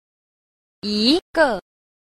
6. 一個 理 – yīgè lǐ – nhất cá lý (một lý)